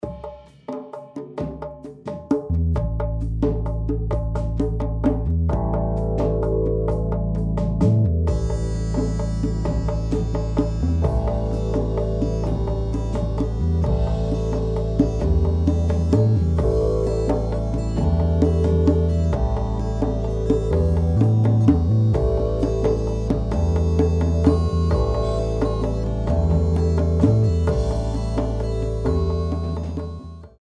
three beats